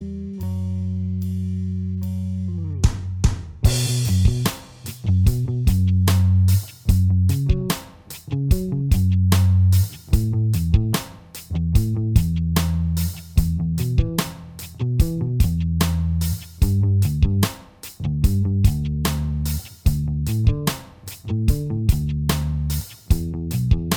Minus Guitar Solos Soft Rock 6:34 Buy £1.50